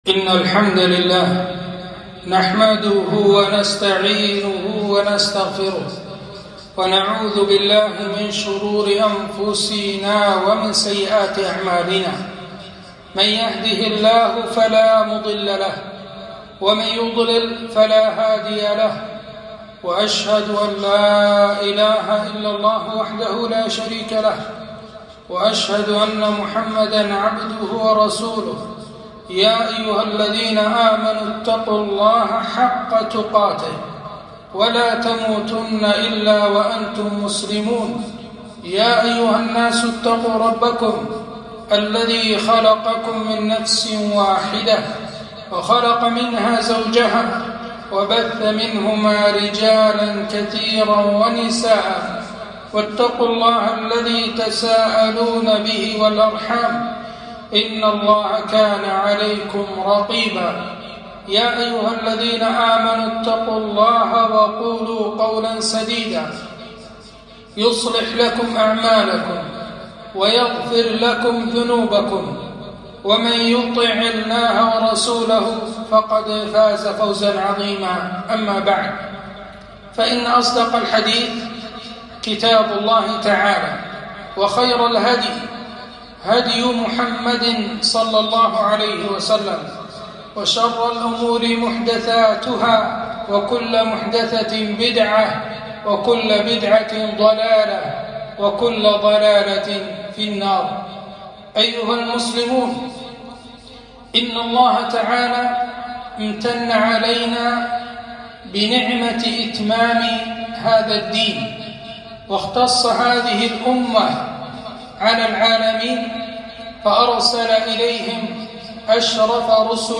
خطبة - خطورة الرشوة